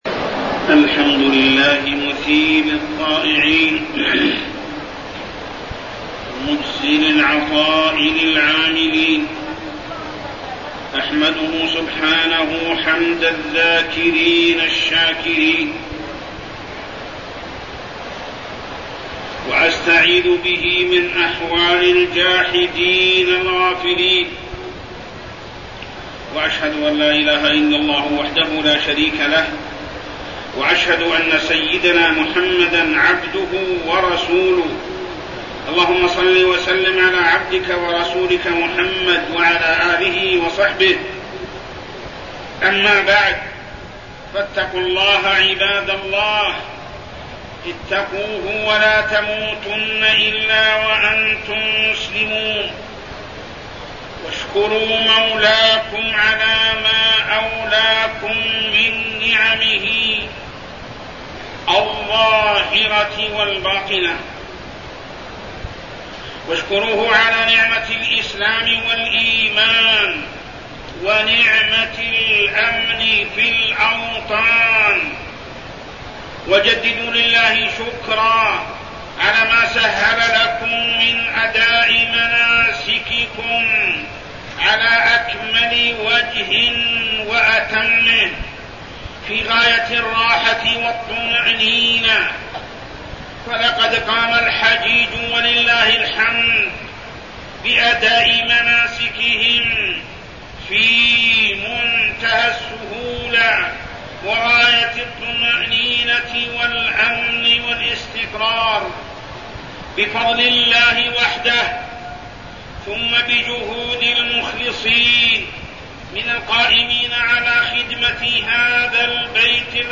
تاريخ النشر ١٨ ذو الحجة ١٤٠٩ هـ المكان: المسجد الحرام الشيخ: محمد بن عبد الله السبيل محمد بن عبد الله السبيل خدمة ضيوف الرحمن